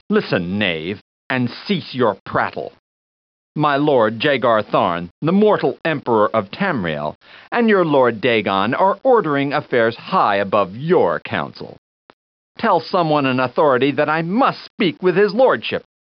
Voice line of Reply 3 from Sirran Angada in Battlespire.